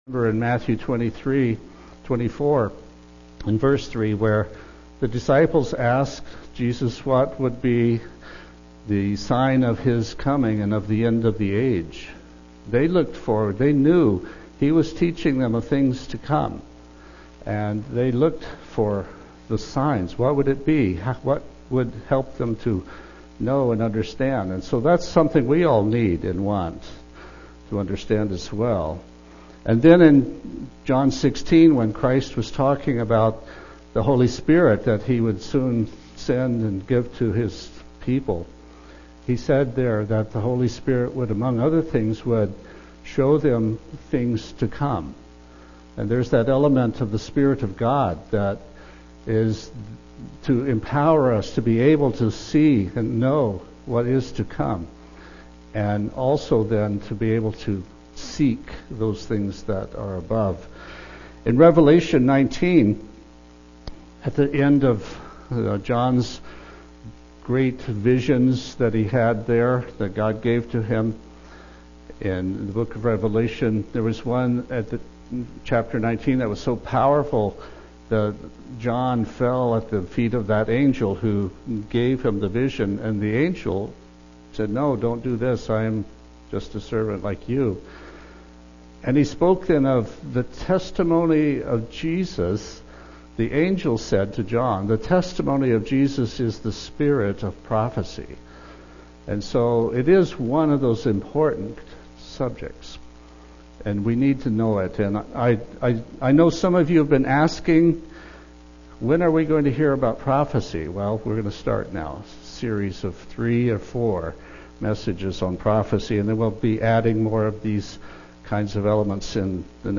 Given in Olympia, WA
UCG Sermon Studying the bible?